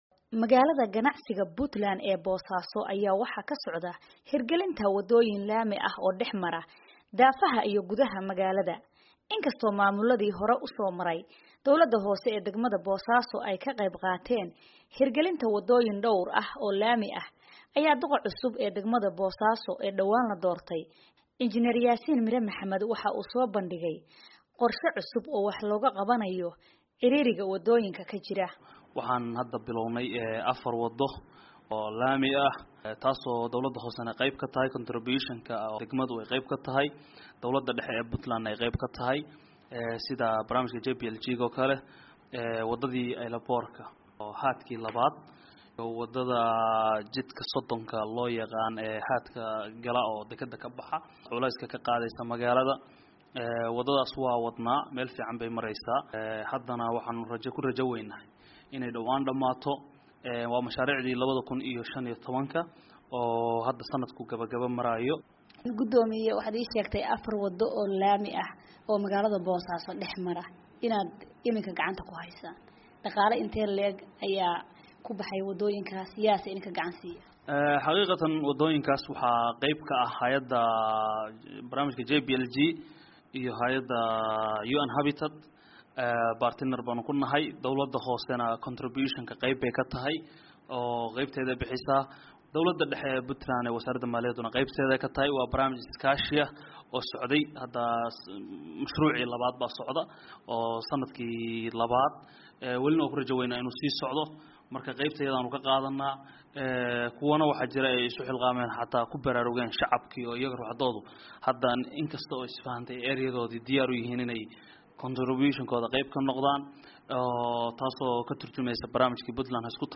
Warbixinta Boosaaso